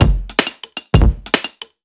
OTG_Kit9_Wonk_130a.wav